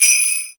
Index of /musicradar/essential-drumkit-samples/Shaken Perc Kit
Shaken Bells 01.wav